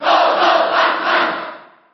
File:Pac-Man Cheer JP SSB4.ogg
Pac-Man_Cheer_JP_SSB4.ogg.mp3